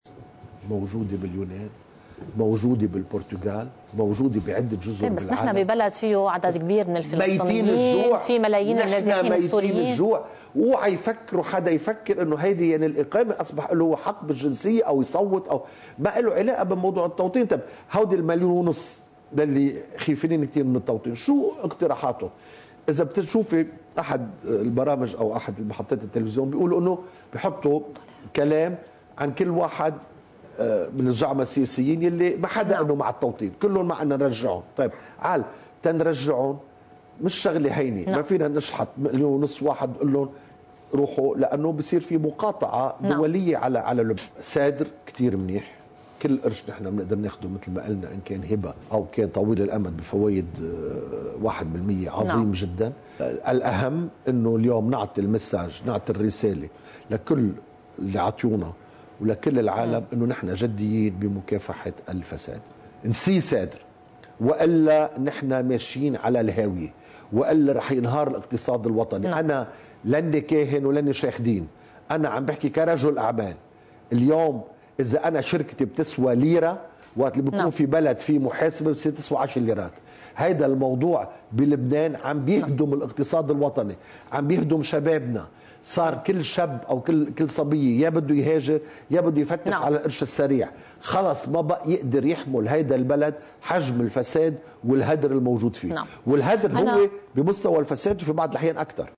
مقتطف من حديث الوزير السابق فادي عبود لقناة “الجديد”: (10 نيسان 2018)